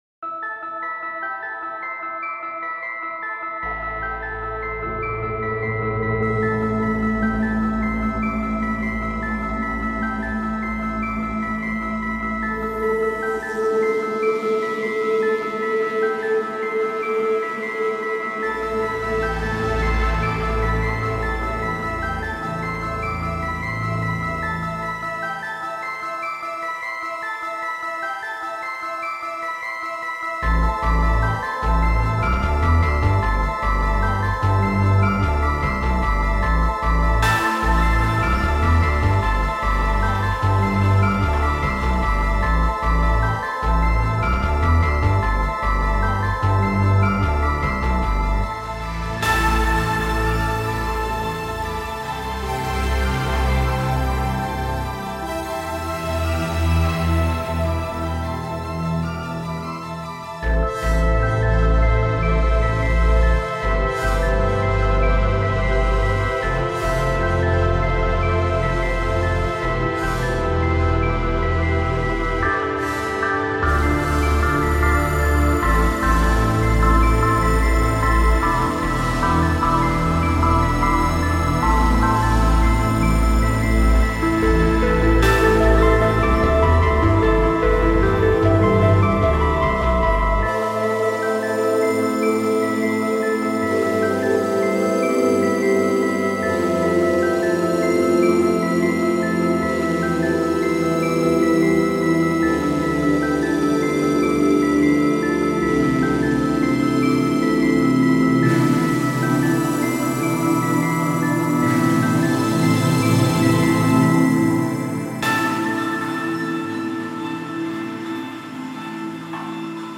合成环境铺底 Zero-G Extreme Modular Environments KONTAKT-音频fun
使用的声音旨在用于各种项目，但从广义上讲，它们特别适合电影或实验音乐，您需要大气，丰富和现代感的东西。
这增加了超出您通常可能遇到的标准混响的深度和大气细节。
这些快照已被分类为各种类型的声音，例如大气，键，打击垫，主音，复合合成器，低音和FX。